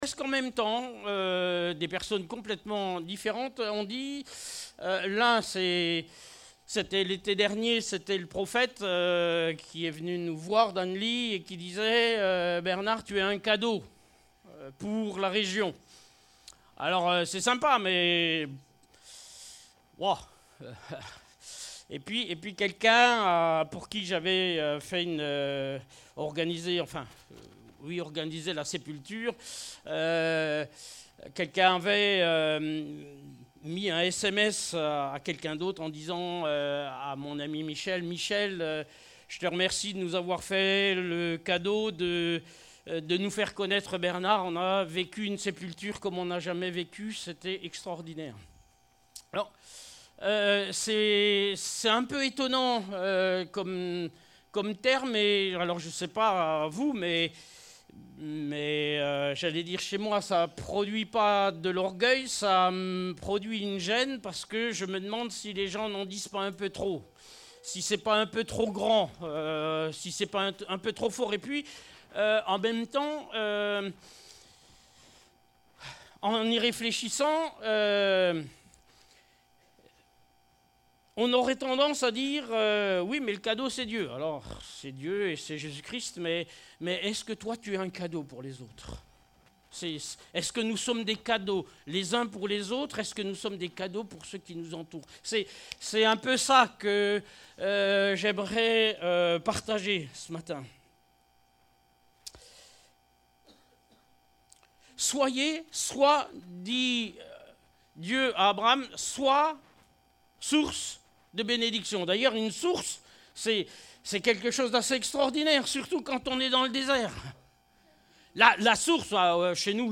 Passage: Génèse 12:1-2 Type De Service: Culte